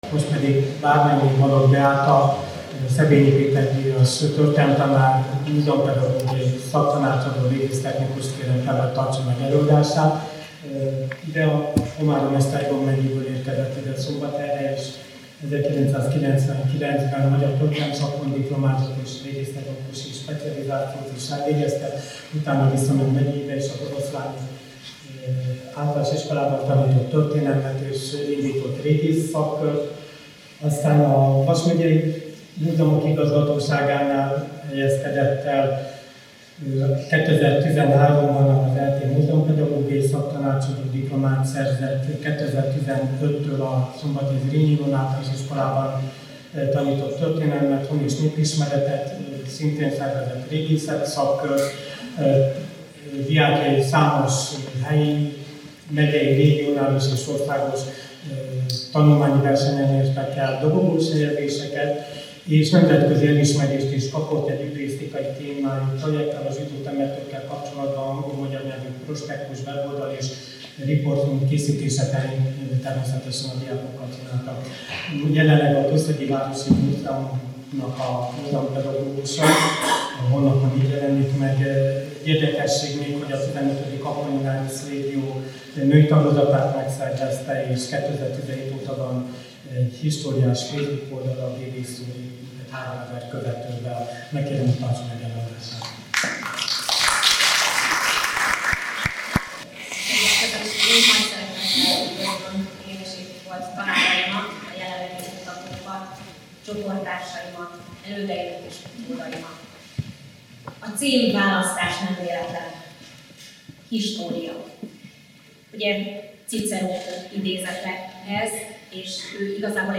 Rendezvény a szombathelyi történelem szakos tanárképzés indulásának és a Történelem Tanszék alapításának 50 éves jubileuma alkalmából.